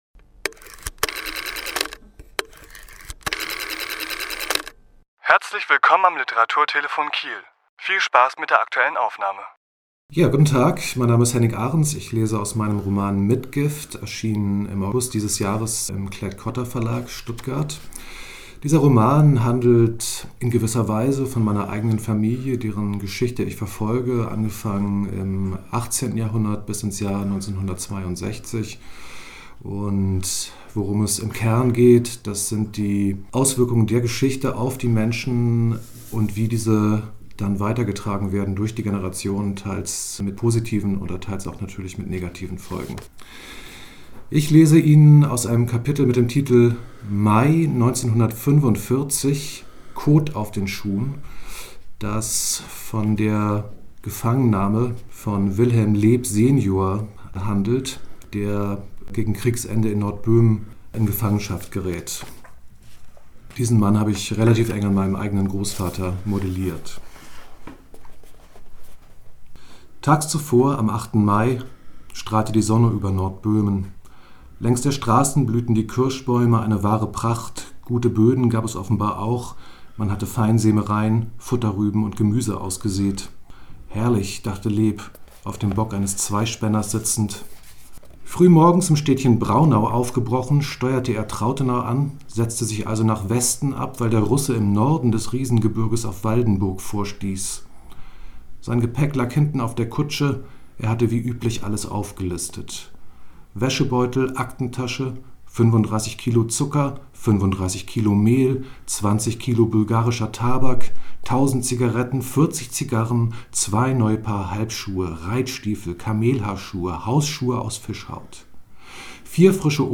Autor*innen lesen aus ihren Werken
Die Aufnahme entstand im Rahmen einer Lesung am 25.11.2021 im Literaturhaus Schleswig-Holstein.